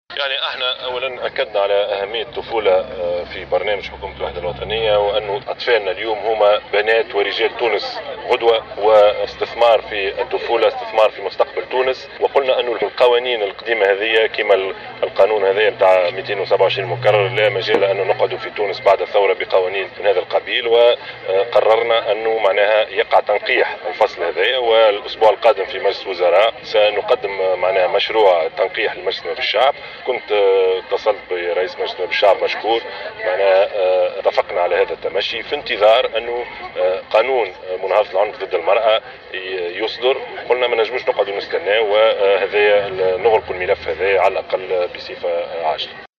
أعلن رئيس الحكومة يوسف الشاهد على هامش إشرافه اليوم الجمعة 16 ديسمبر 2016 على تظاهرة إعطاء إنطلاق نشاط نوادي الأطفال المتنقلة أنه سيتم تنقيح الفصل 227 مكرر من المجلة الجزائية.